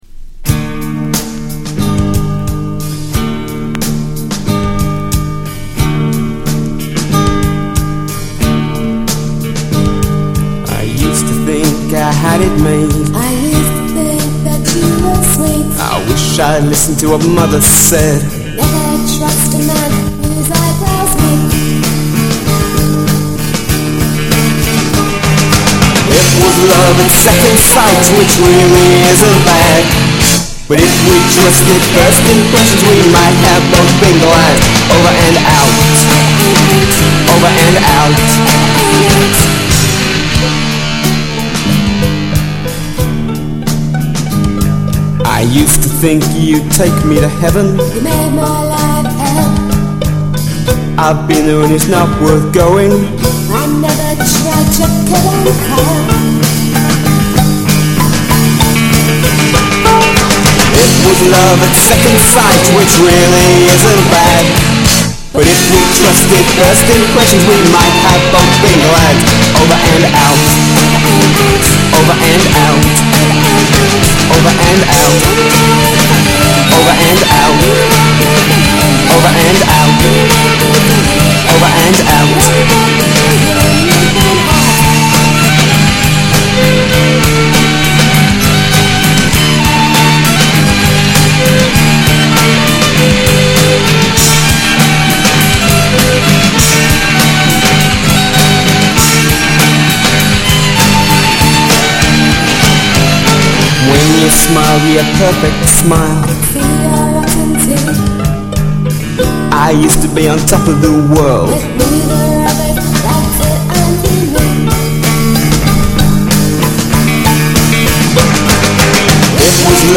the classic indiepop sound